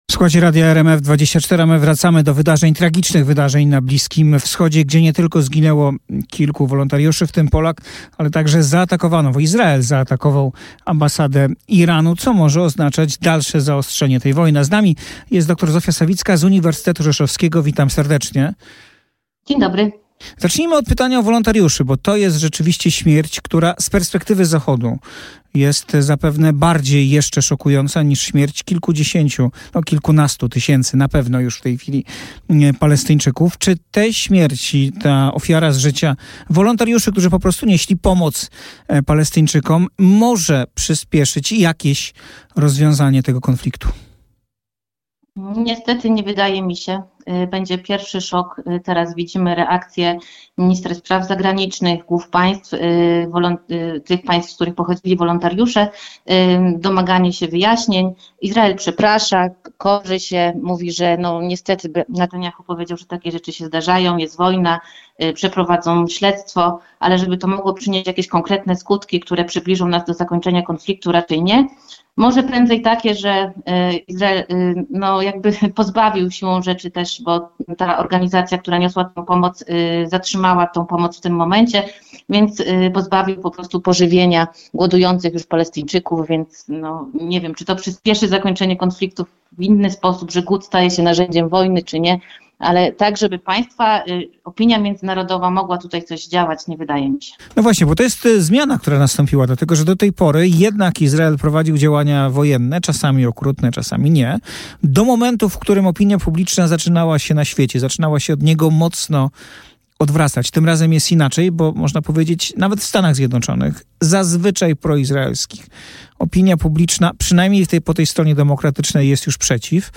08:00 Fakty i Poranna rozmowa w RMF FM - 03.10.2024